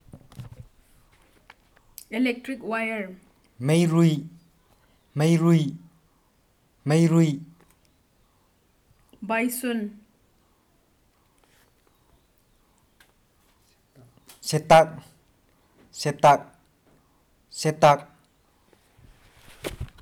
Personal narrative about the occupation, hunting-fishing, and judiciary systems in the ancient and present Chiru society